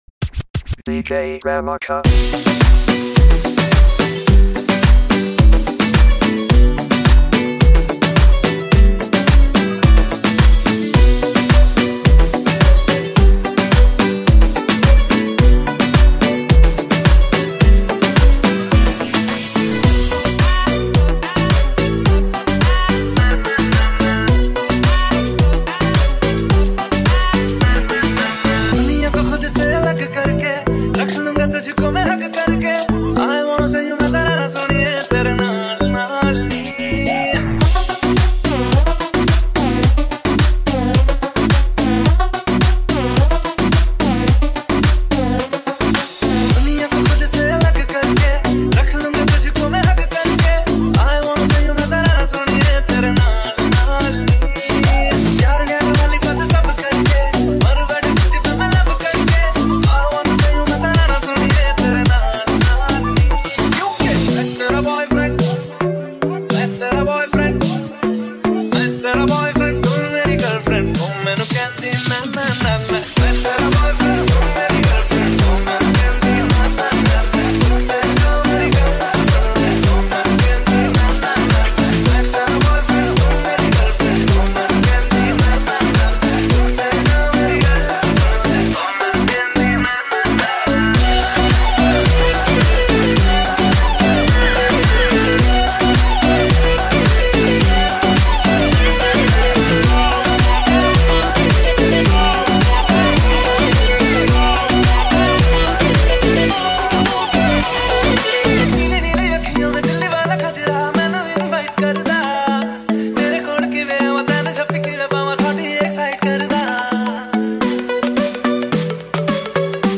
EDM Remix